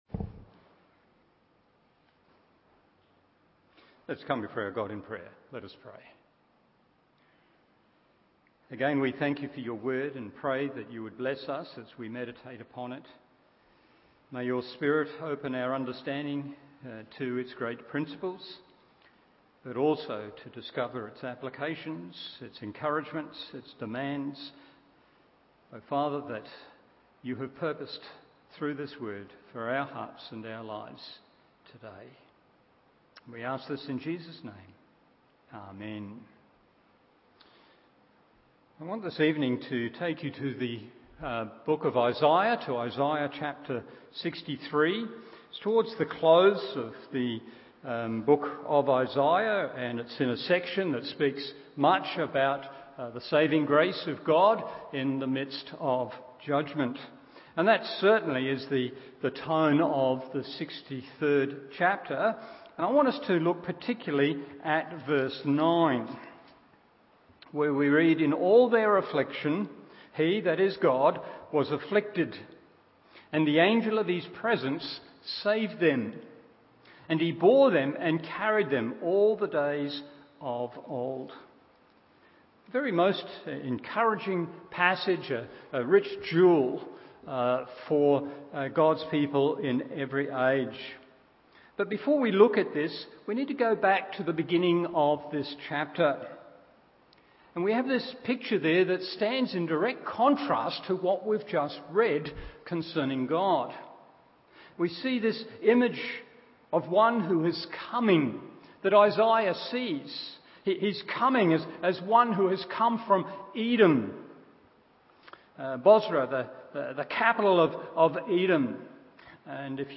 Evening Service Isaiah 63:9 1.